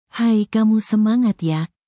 Text to Speech v1
text2speech_1